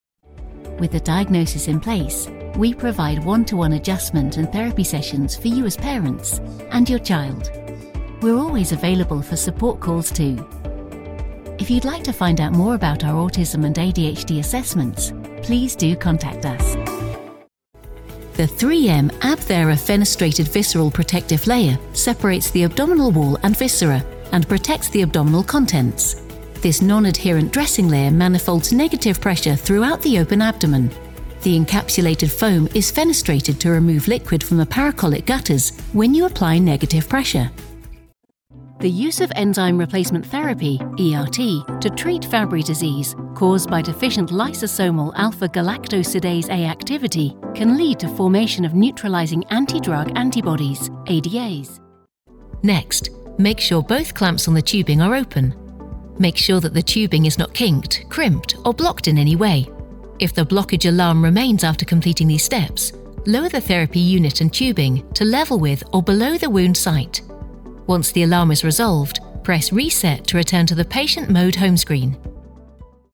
Medical Narration
I have my own studio where I record and edit to the highest professional standards, offering live direction where required.
Rode NT-1A microphone
Young Adult
Middle-Aged
Mezzo-SopranoSoprano